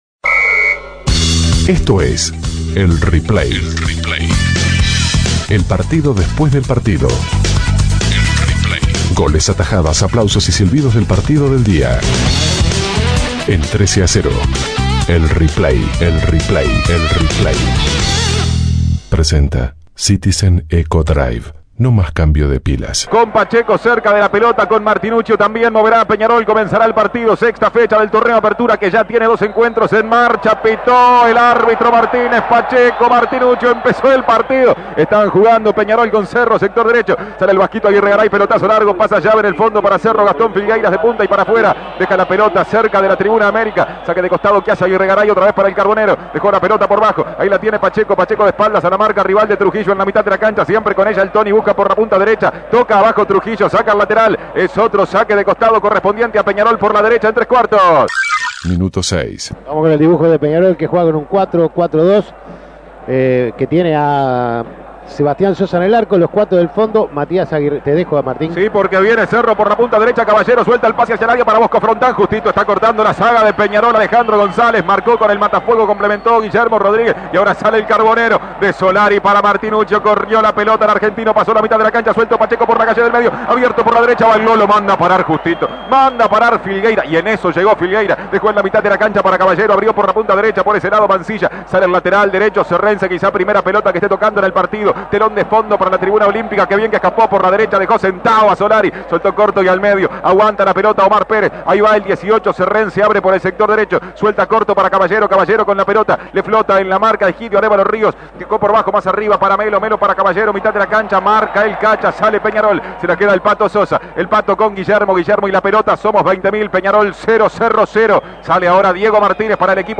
Goles y comentarios Escuche el replay de Nacional - Liverpool Imprimir A- A A+ Nacional empató con Liverpool 0 a 0 en el Parque Central por la sexta fecha del torneo Apertura 2010.